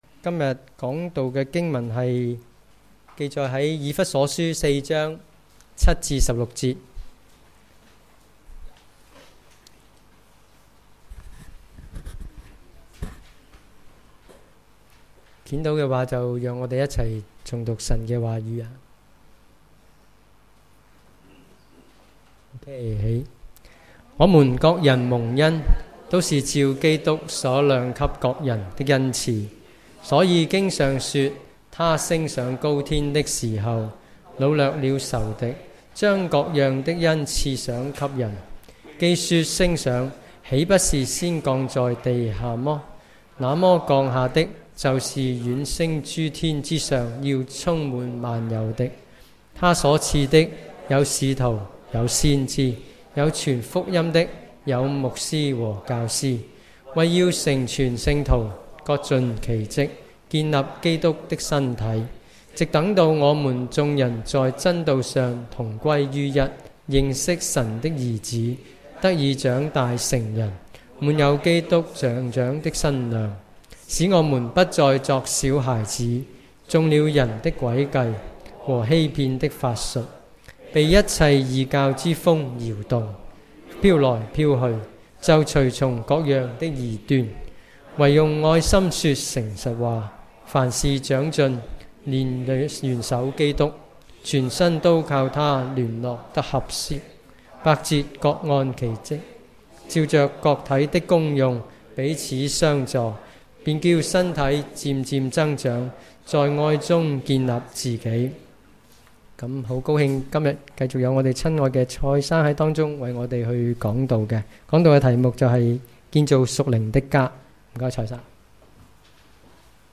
主日崇拜講道-建造屬靈的家